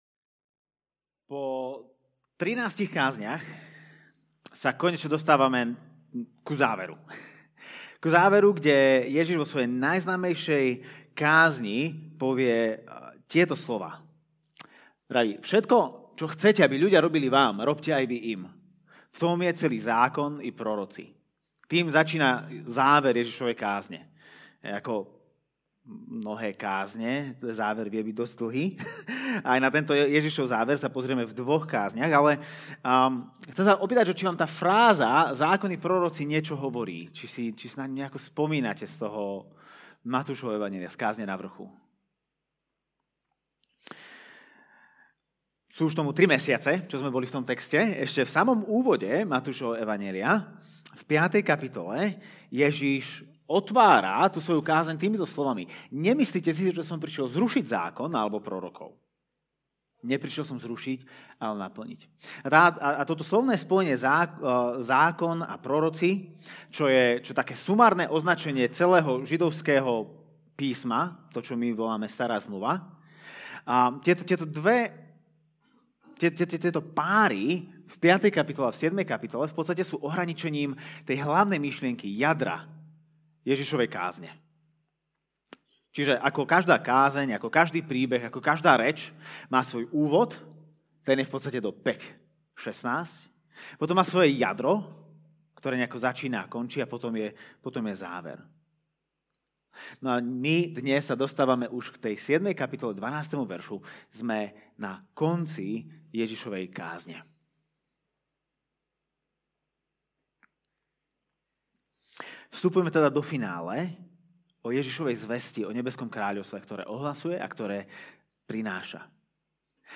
- Podcast Kázne zboru CB Trnava - Slovenské podcasty